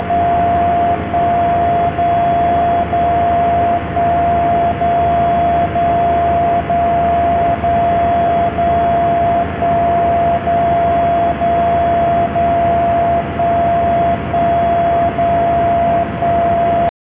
DCF77_Time_Signal.mp3